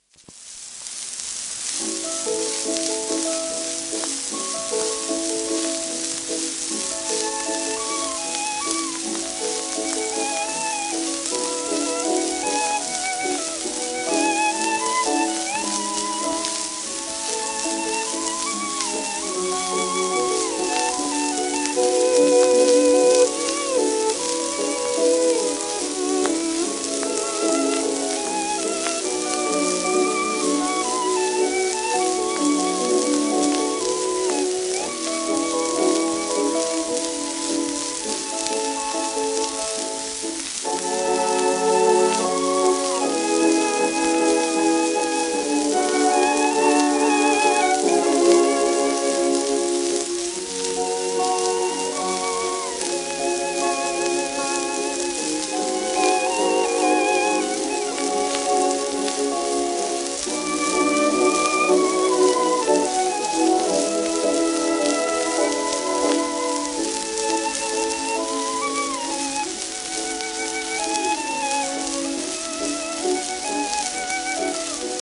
w/オーケストラ
旧 旧吹込みの略、電気録音以前の機械式録音盤（ラッパ吹込み）